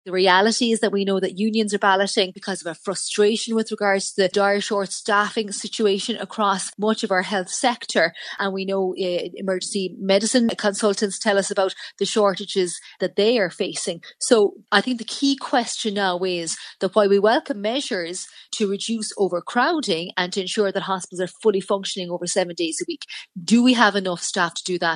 But Labour's Health Spokesperson Marie Sherlock says staff are already struggling to cope: